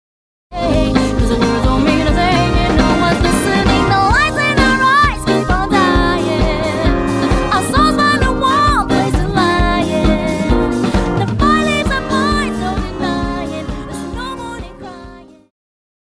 Pop
Electric Guitar
Band
Instrumental
Funk,Disco
Solo with accompaniment